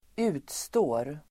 Uttal: [²'u:tstå:r]